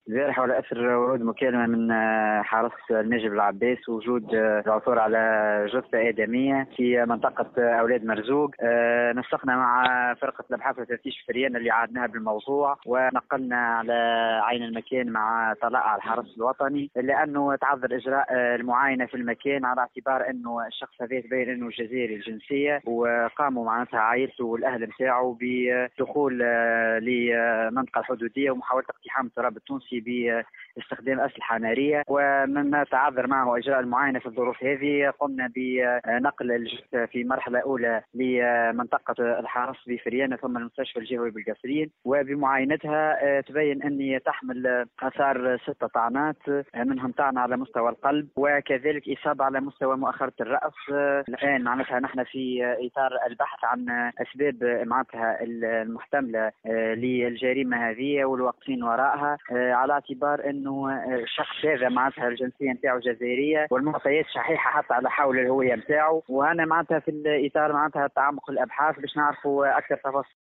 مساعد وكيل الجمهورية بالمحكمة الإبتدائية بالقصرين شوقي بوعزي تحميل المشاركة علي